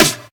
Bright Snare D# Key 172.wav
Royality free snare drum sample tuned to the D# note. Loudest frequency: 2692Hz
bright-snare-d-sharp-key-172-gi6.ogg